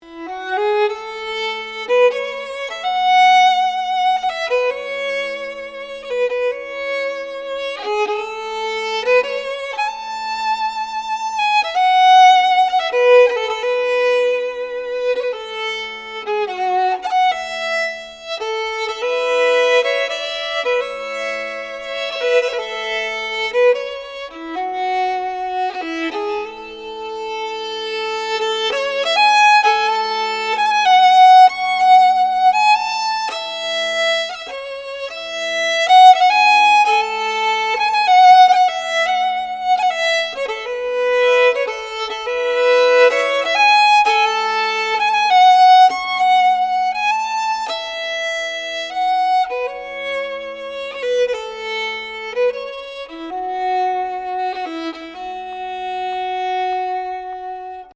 Original compositions Fiddle tunes Sad Fiddler.